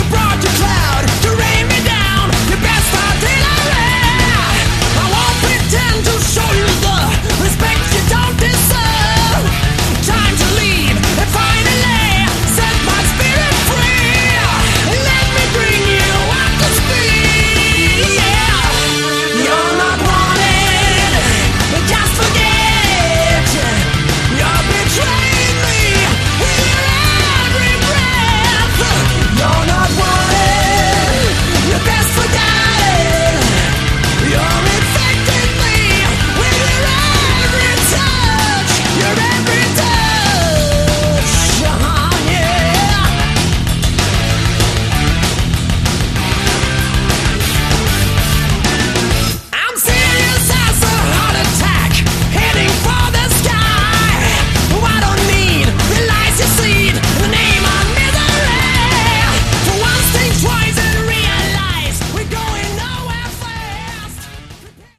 Category: Melodic Rock
keyboards, Hammond Organ